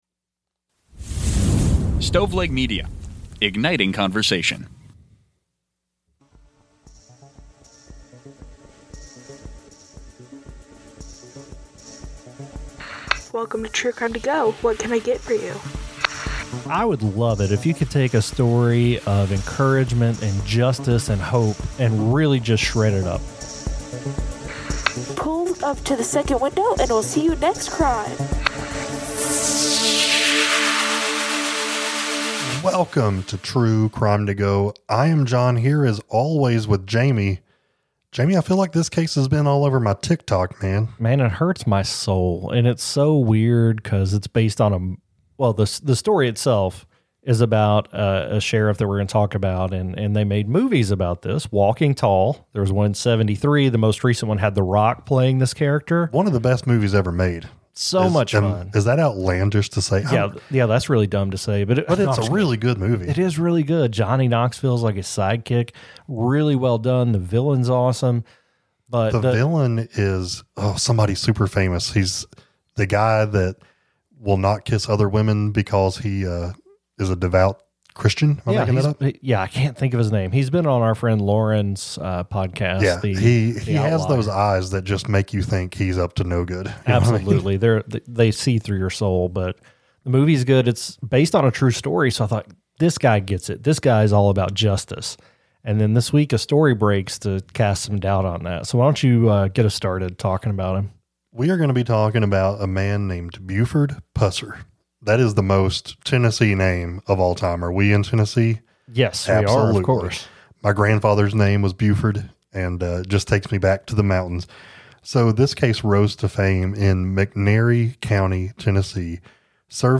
True Crime, Society & Culture, Documentary, Personal Journals
Now, a cold-case review of the 1967 crime has revealed stunning new forensic evidence. The hosts discuss how blood spatter analysis and medical examiner findings suggest that Pauline was shot outside the car, and that Pusser's own wound may have been self-inflicted.